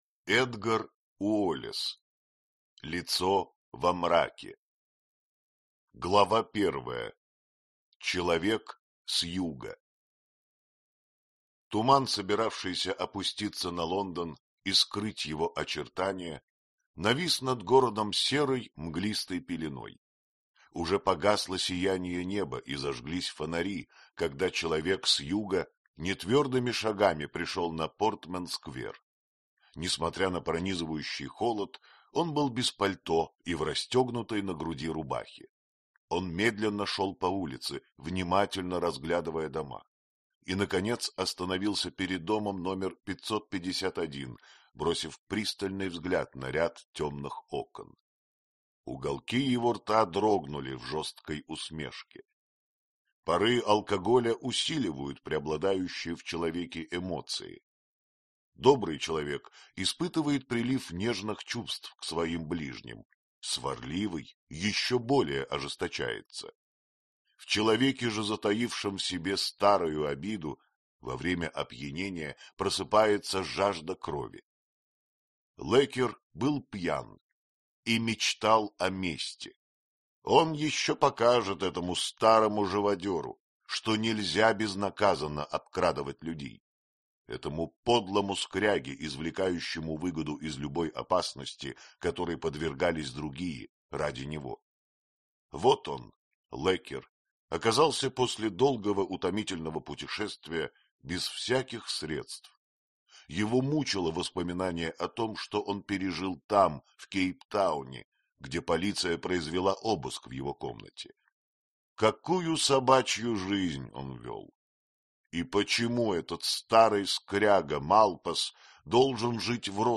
Аудиокнига Лицо во мраке | Библиотека аудиокниг